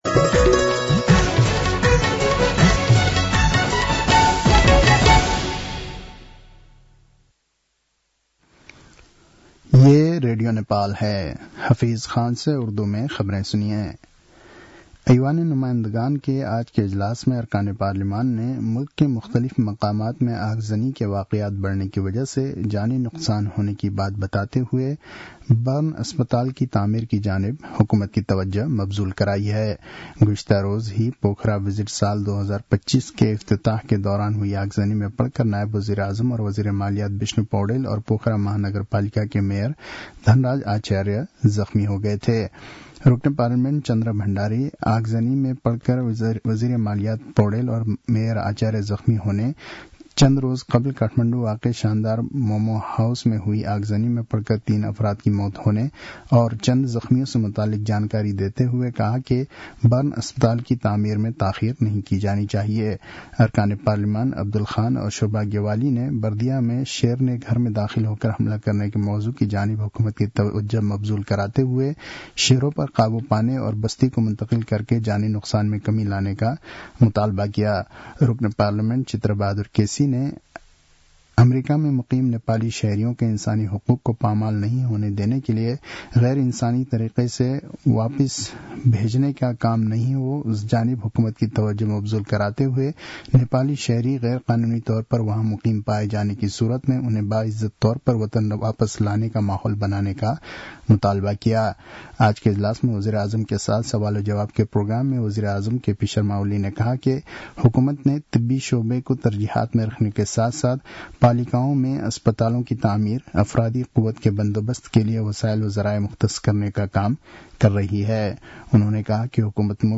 उर्दु भाषामा समाचार : ५ फागुन , २०८१